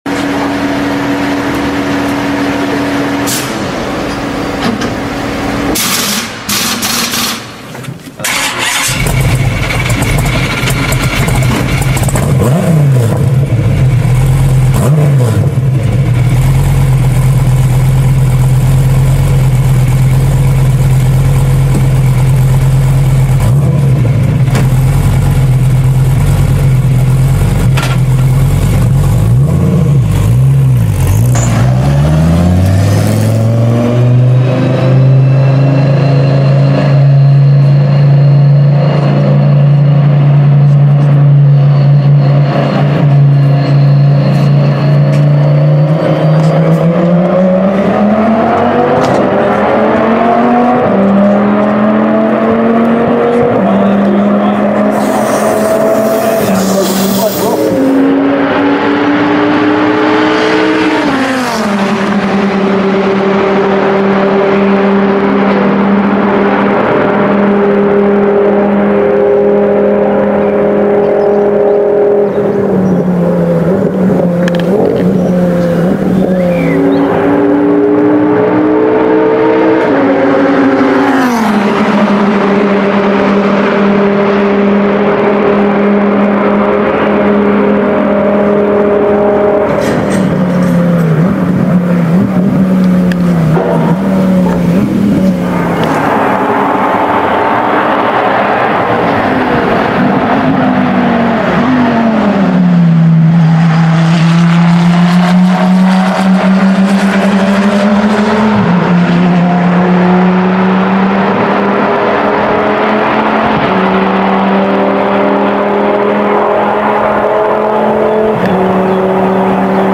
Can’t get enough of a Viper V10 sound, especially in race form.